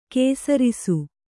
♪ kēsarisu